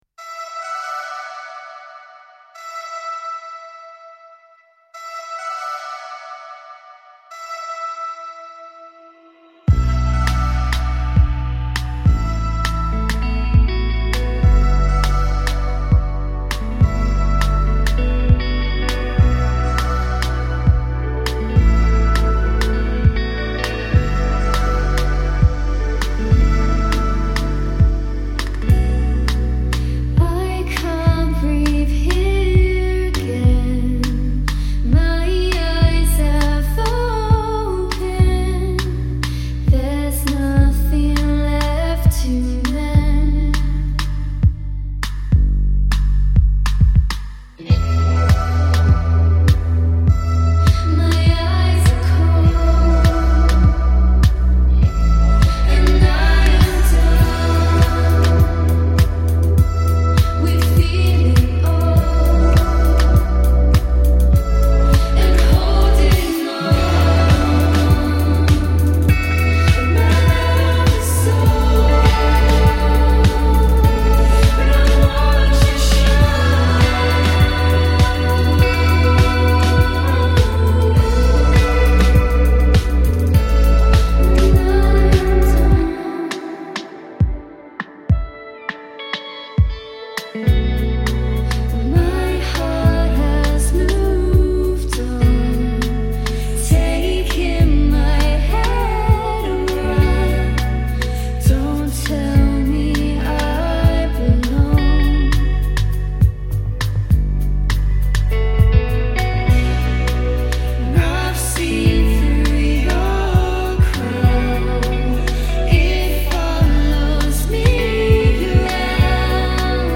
Suffolk duo
with a more electronic sound
manifests daydream vocals with washes